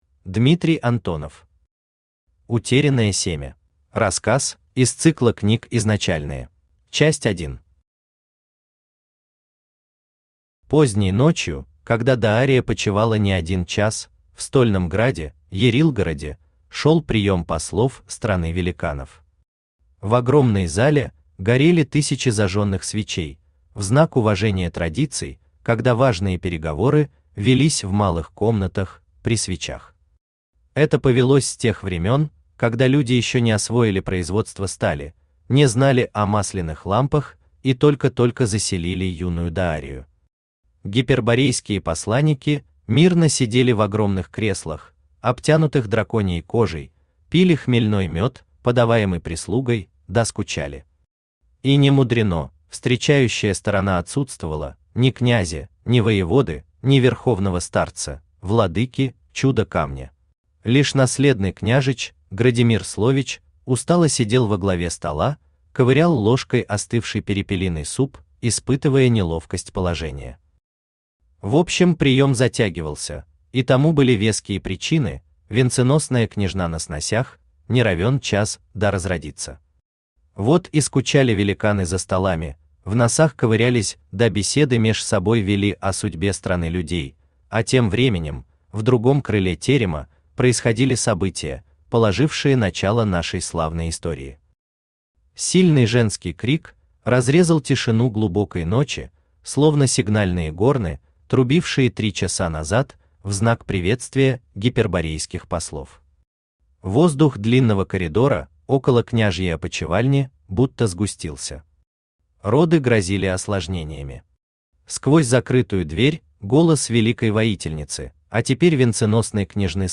Аудиокнига Утерянное семя | Библиотека аудиокниг